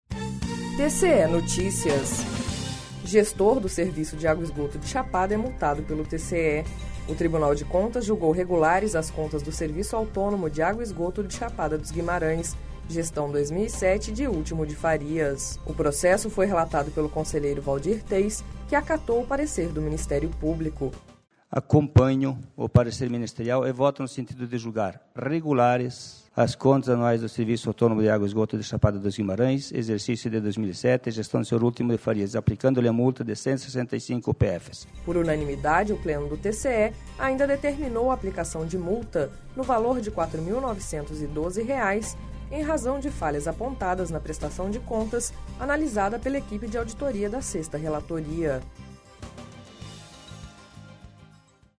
Sonora: Waldir Teis – conselheiro do TCE-MT